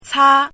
怎么读
chāi